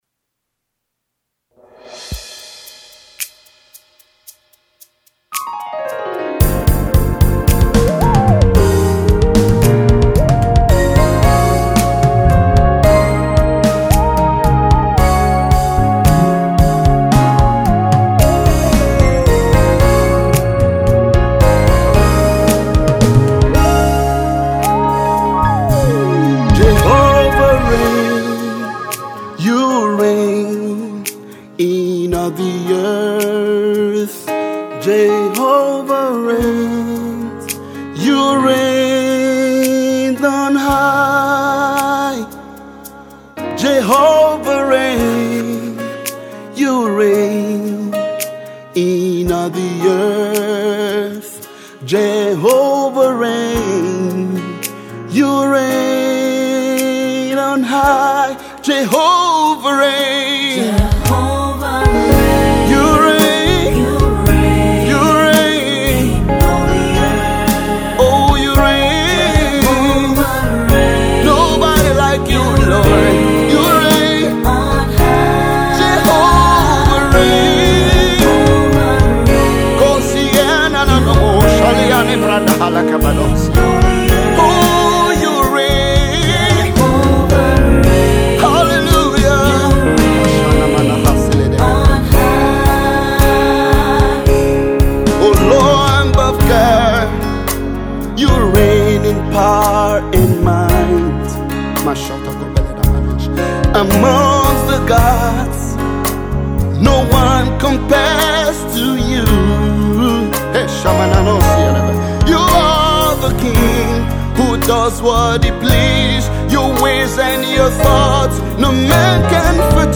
worship
soul-lifting ballad